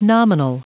Prononciation du mot nominal en anglais (fichier audio)
Prononciation du mot : nominal